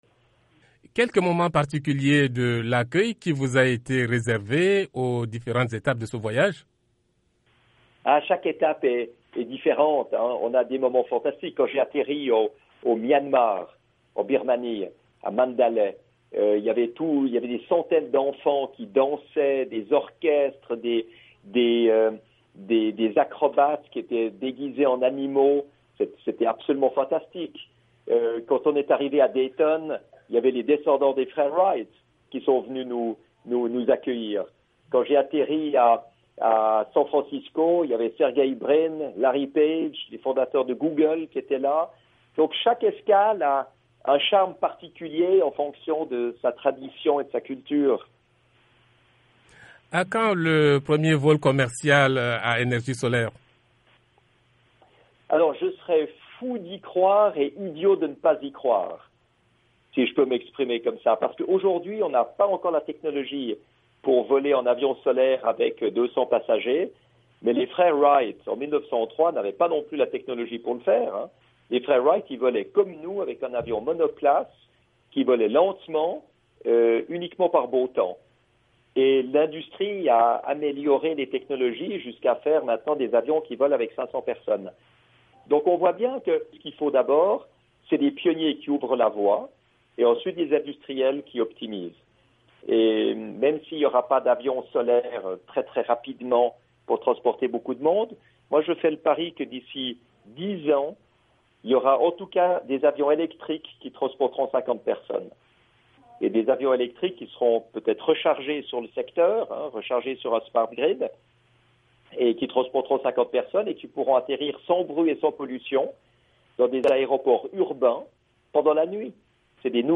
Dans un entretien accordé à VOA Afrique à partir de Dayton, dans l’Etat américain de l’Ohio, Bertrand Piccard, a rappelé qu’il vient d’une famille d’explorateurs.
Entretien avec Bertrand Piccard (4)